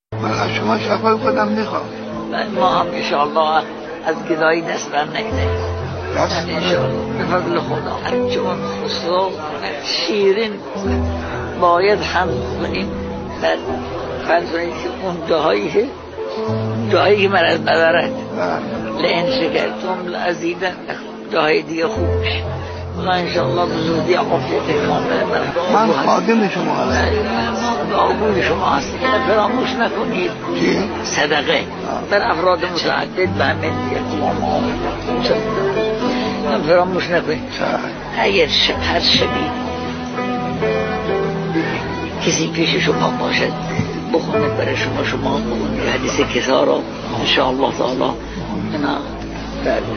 صوت ســـخنرانی:
سخنران: آیت‌الله بهجت.